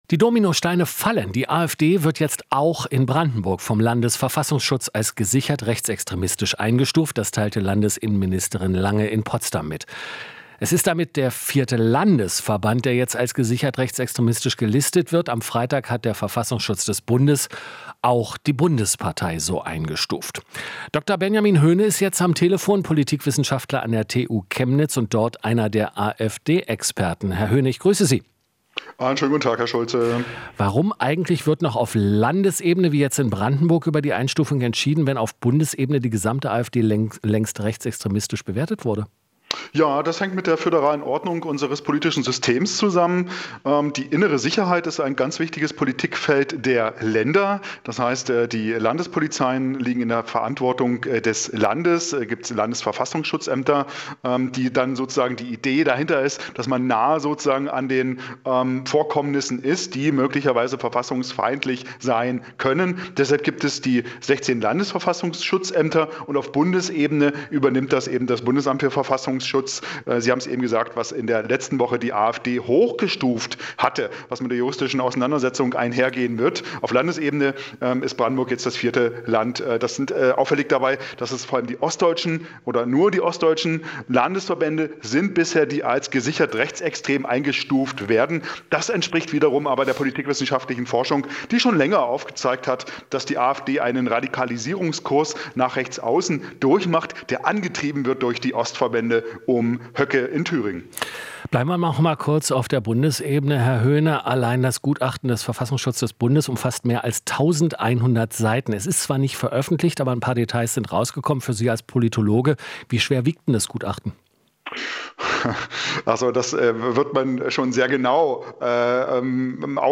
Interview - Politologe: Ostverbände treiben Radikalisierung der AfD an